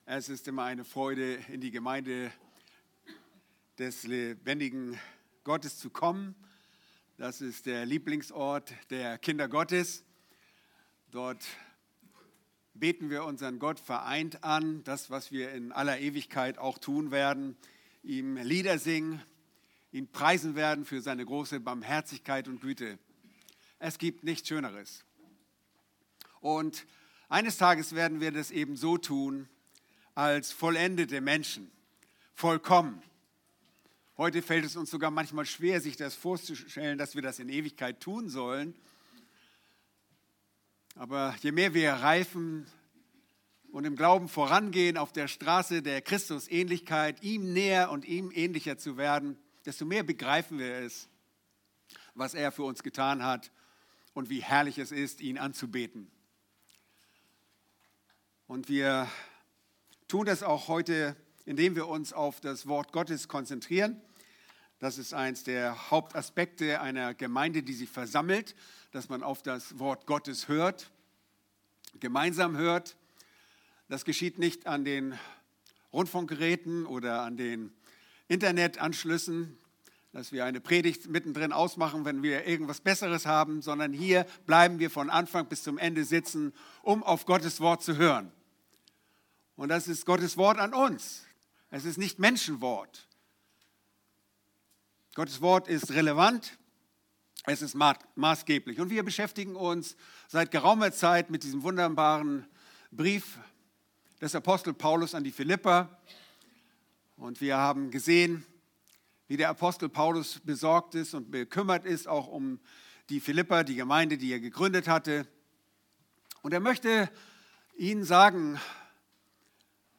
Aktuelle Predigten - Bibelgemeinde Barnim